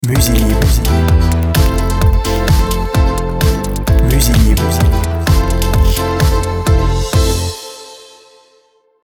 dynamique, sport
BPM Rapide